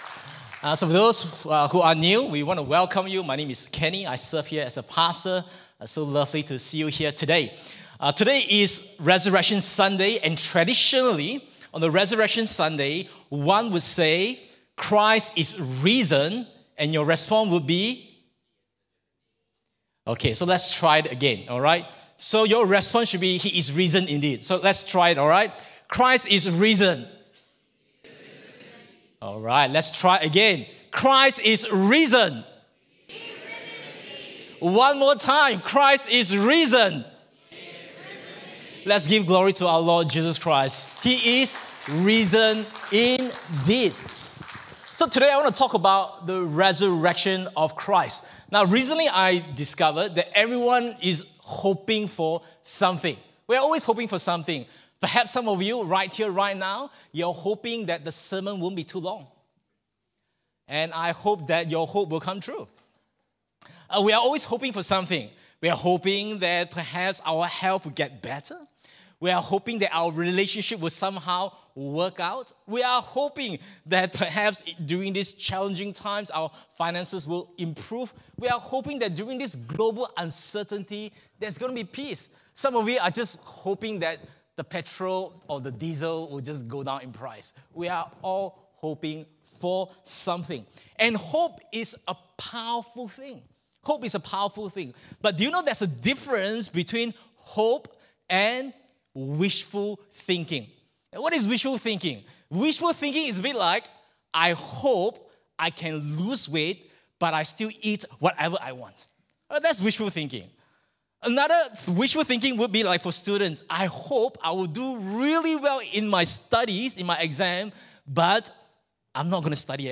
English Sermons | Casey Life International Church (CLIC)
Easter Sunday Service